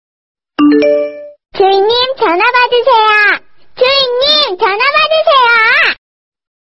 Nada notifikasi tuan/pemilik jawab telponnya Versi Korea
Kategori: Nada dering
Keterangan: Download notifikasi tuan/pemilik jawab telponnya (주인님 전화받으세요) dengan suara anak Korea yang imut.